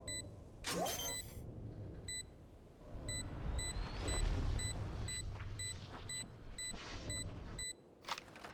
Valorant Spike Defuse Sound Effect Free Download